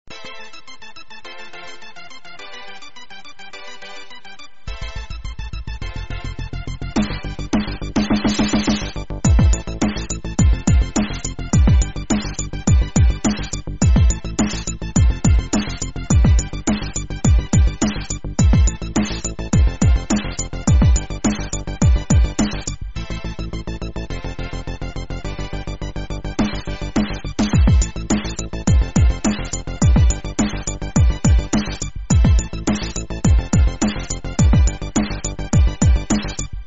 Estilo: Pop
Pista musical para jingles estilo “pop”
Calidad de la muestra (48kbps) ⬅Dale click al Play